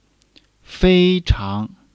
fei1--chang2.mp3